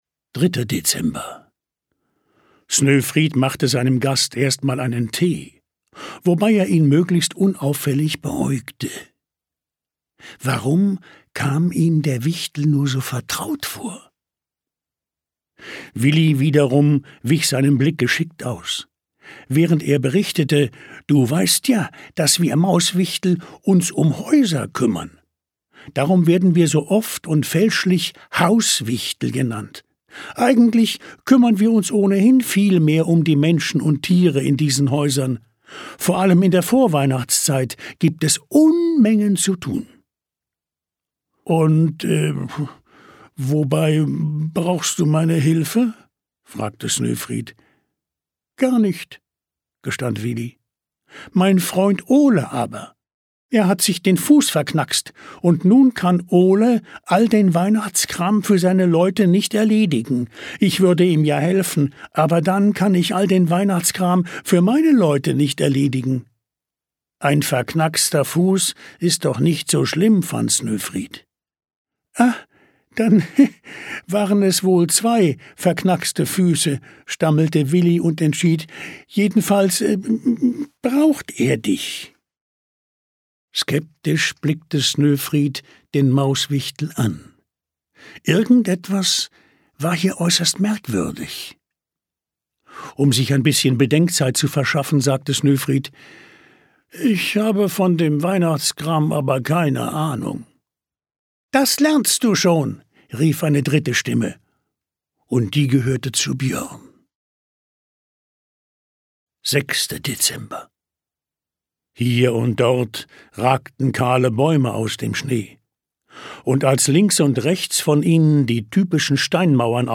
24 Adventskalender-Geschichten und Lieder